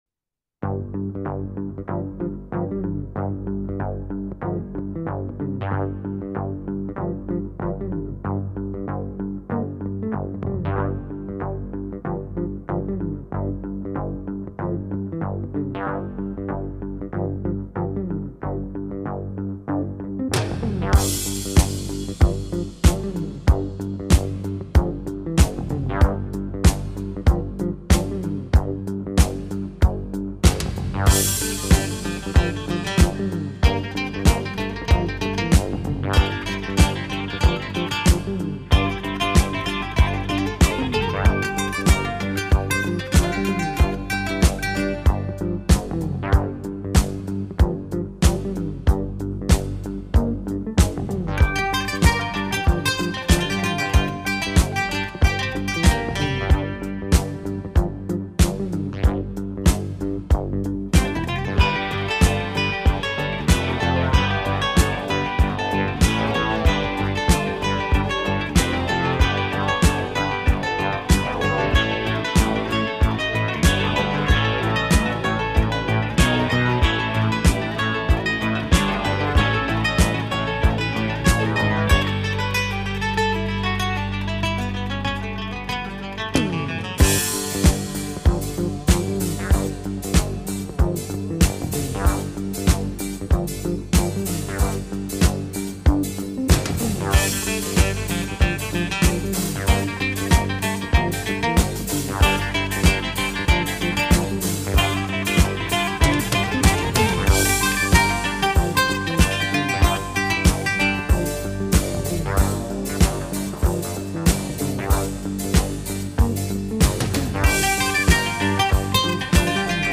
一种节奏，来自电子管风琴，没有打击演奏！
现在听起来还是那样的轻盈活泼，充满遐想